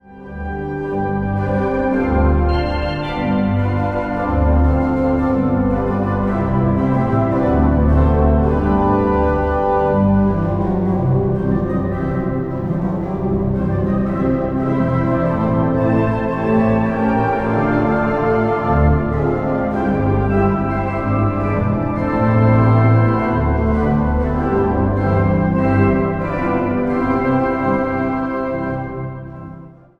Zang | Gemengd koor
Zang | Mannenkoor
Zang | Solozang